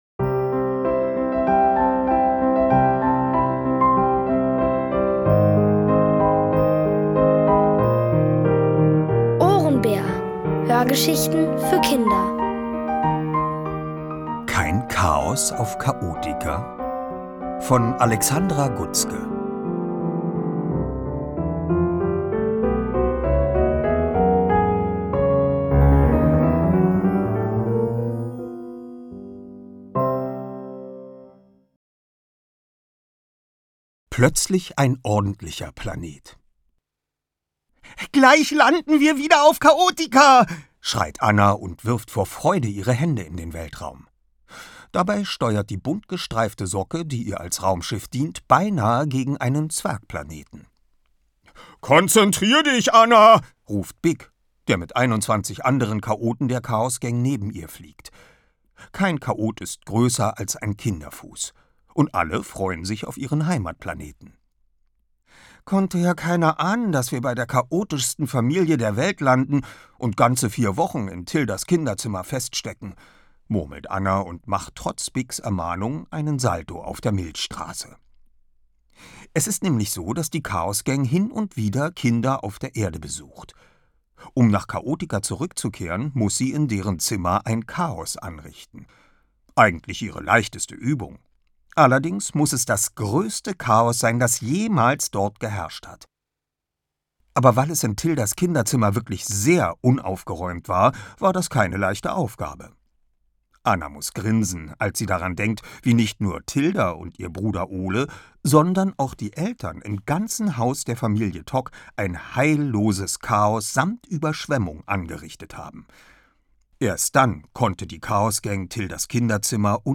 Von Autoren extra für die Reihe geschrieben und von bekannten Schauspielern gelesen.
OHRENBÄR-Hörgeschichte: Kein Chaos auf Chaotika?! (Folge 1 von 4)
Es liest: Oliver Rohrbeck.